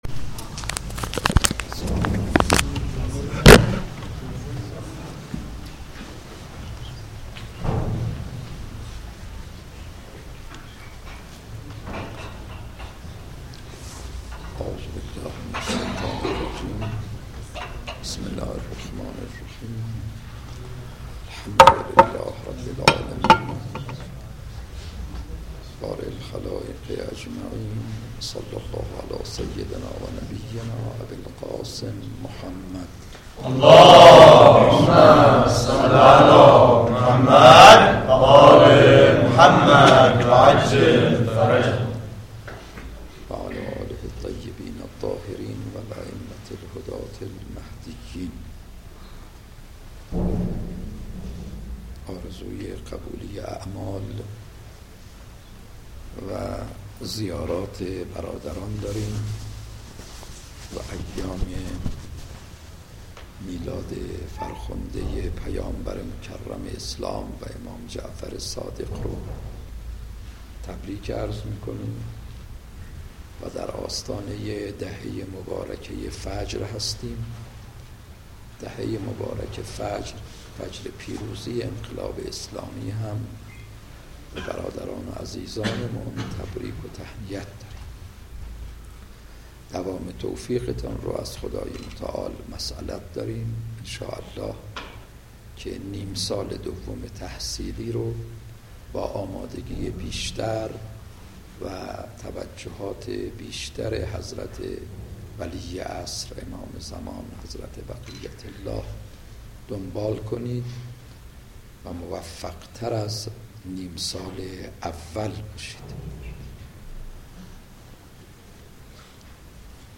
درس اخلاق آیت الله ایمانی